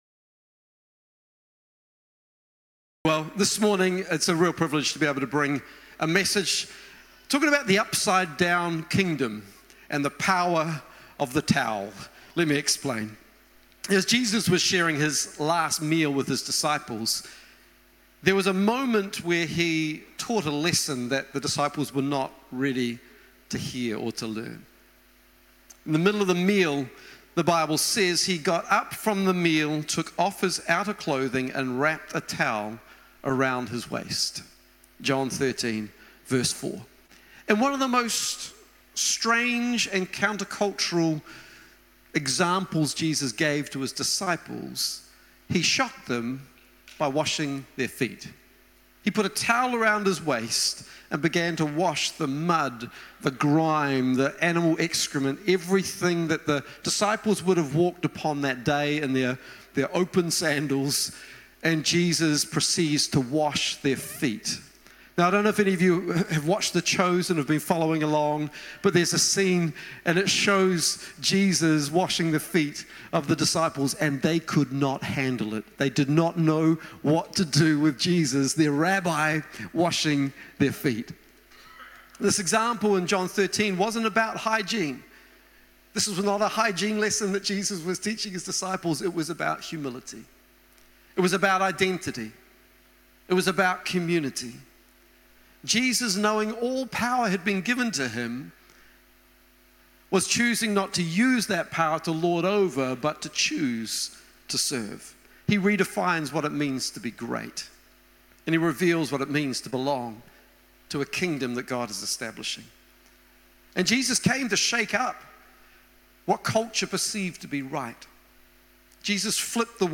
Sunday Messages Upside Down Kingdom